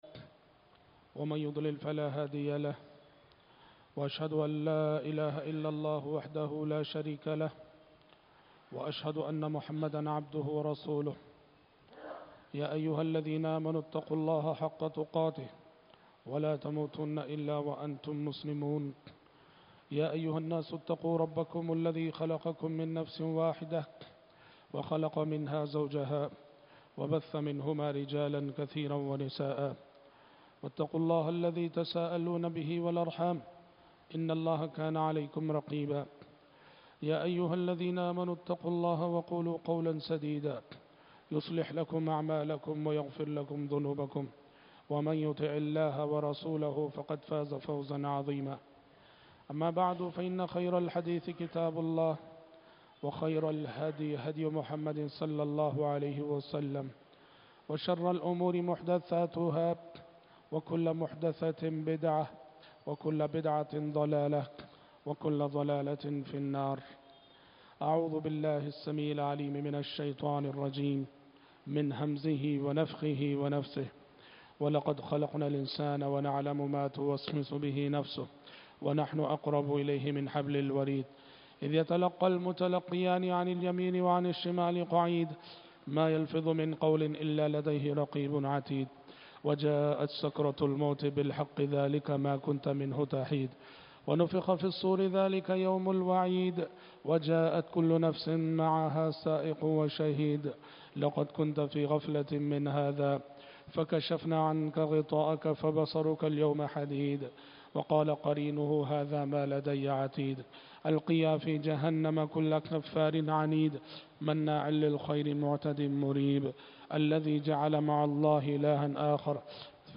BEHTAREEN KHUTBA MAREEZ K LIYE